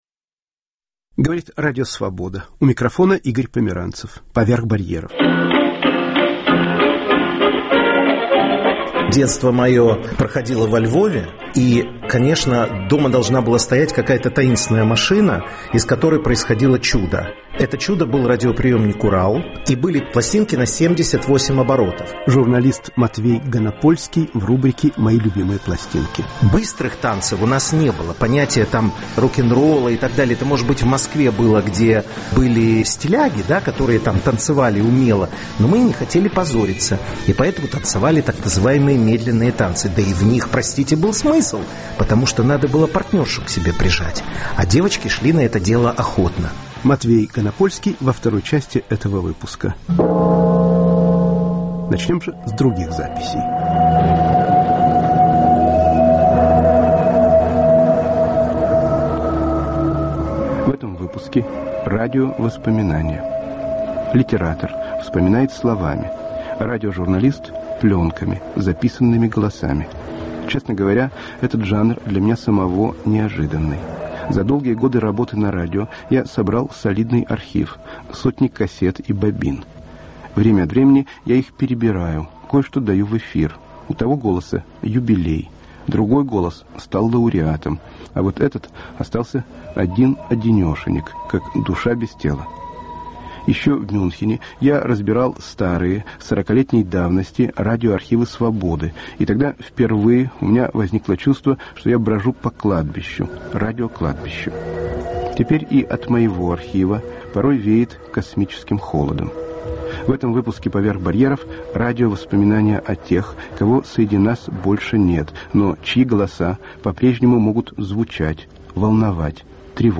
"Радиовоспоминания" В передаче звучат голоса людей, которых больше с нами нет (И.Бродский, Вл.Соколов, А.Синявский и др.) "Наши современники" Рассказывает бездомный из Вятки "Мои любимые пластинки" с журналистом Матвеем Ганапольским